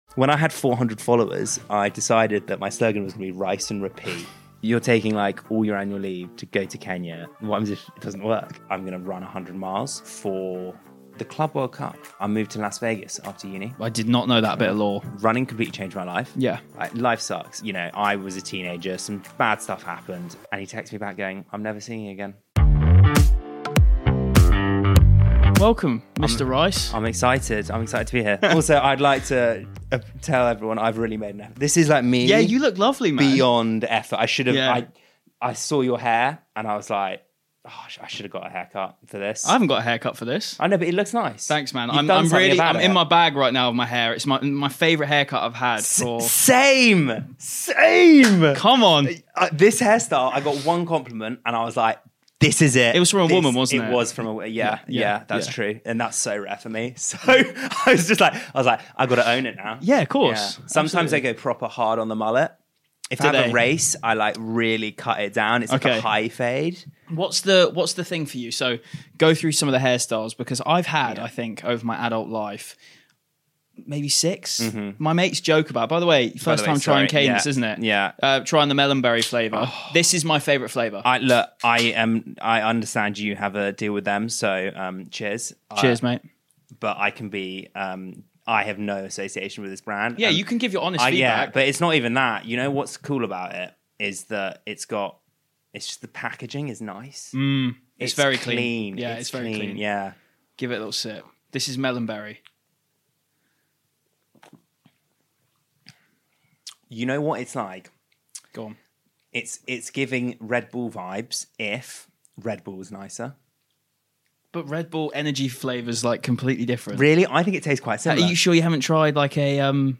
This week I'm joined by content creator & 2:19 marathon runner